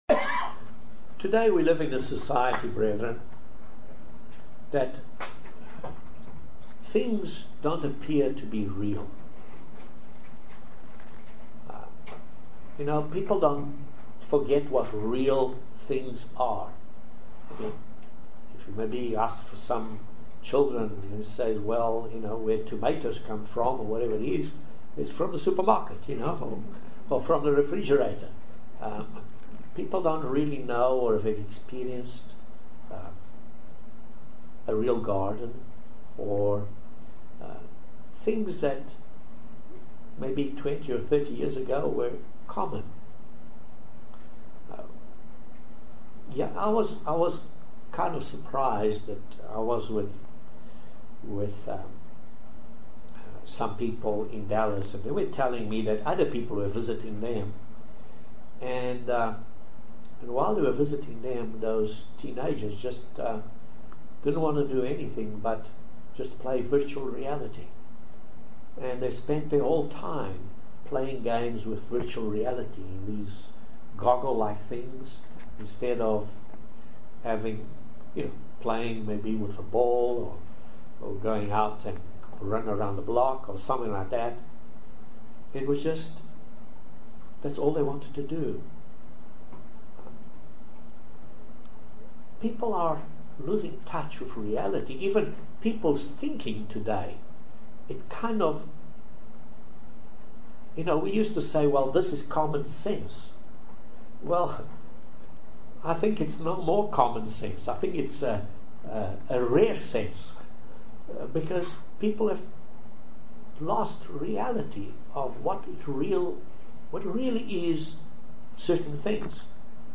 Join us for this very insightful sermon on the true facets of a Christian. Can the world see Christ in us?